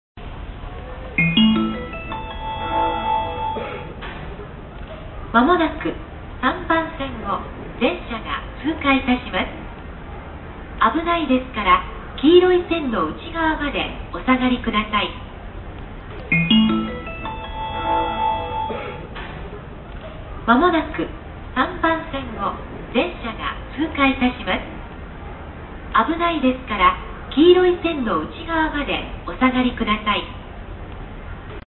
通過接近放送(電車)通過放送です。